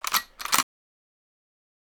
TM-88 FX #09.wav